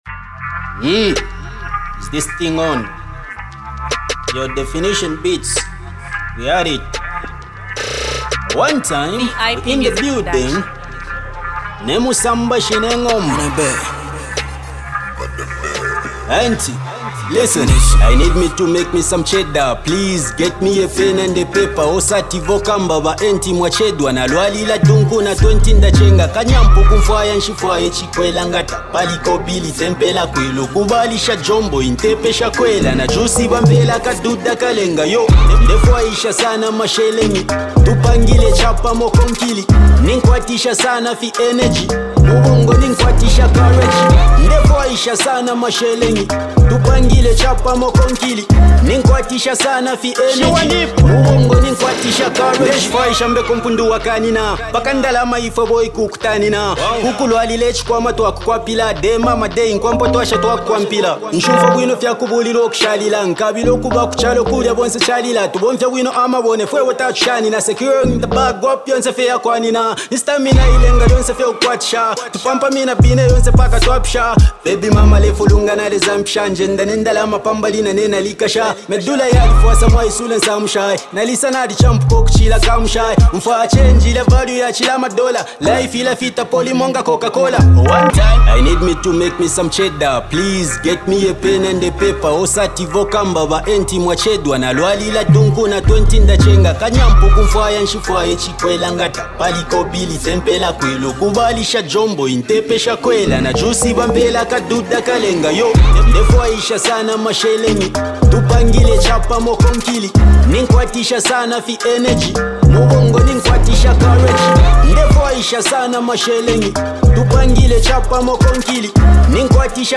a brand new money anthem
rapper